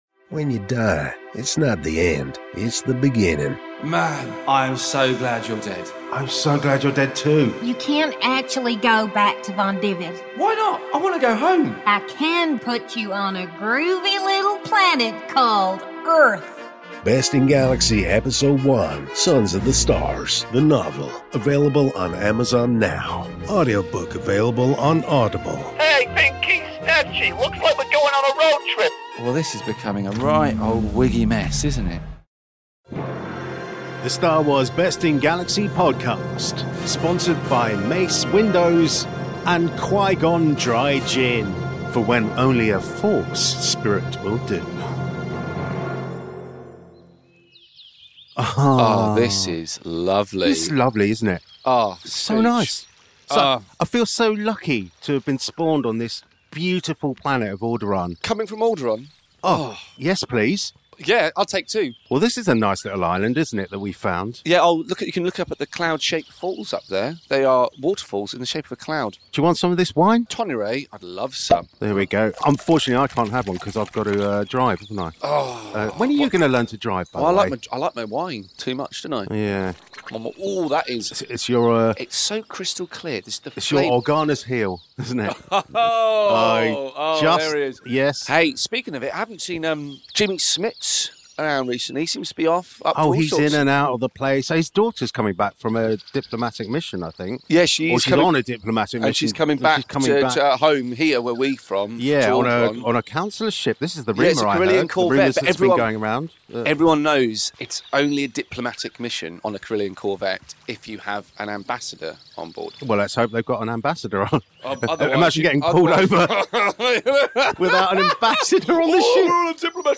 S03 Ep1 of the only improvised unofficial Star Wars based parody sitcom podcast in the galaxy!